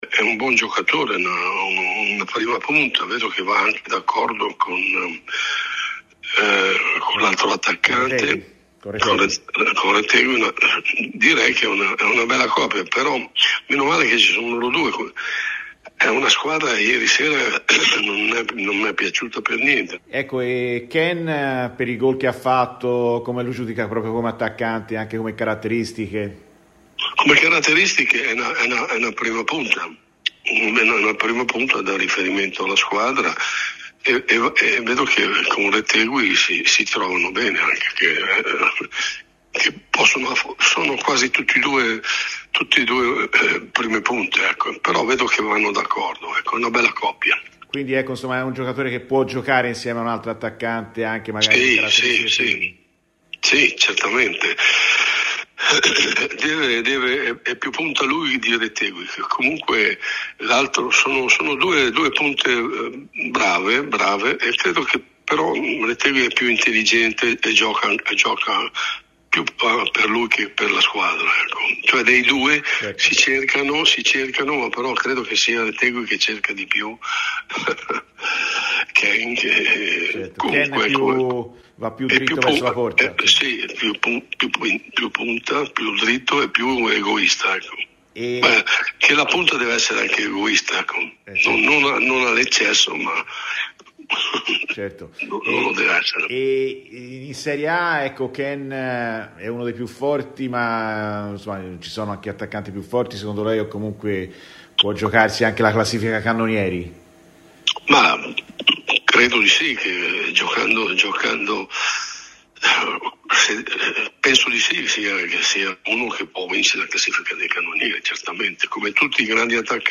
Roberto Boninsegna, ex attaccante, è intervenuto ai microfoni di Radio FirenzeViola durante la trasmissione Viola Amore Mio per parlare del momento che sta vivendo Moise Kean dopo la doppietta con l'Italia di ieri sera: "Kean è un buon giocatore, va molto d’accordo con Retegui, sono una bella coppia.